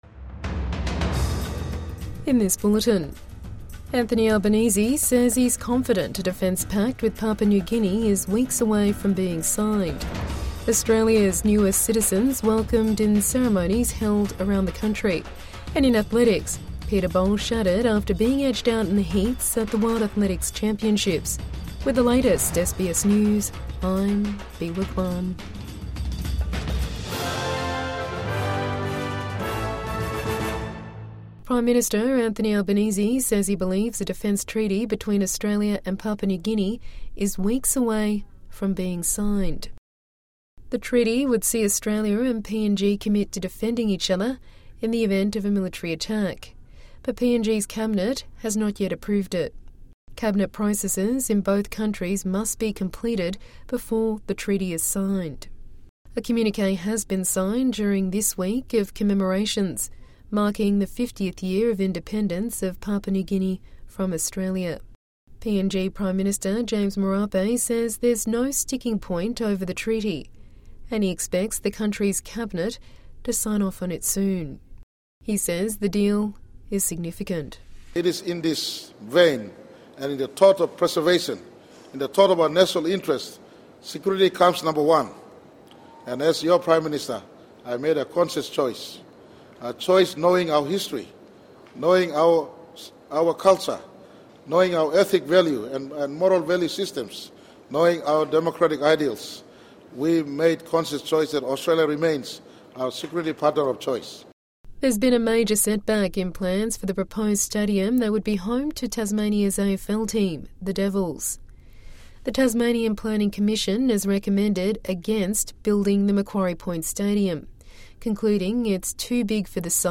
PM says defence deal with PNG to be signed within weeks | Evening News Bulletin 17 September 2025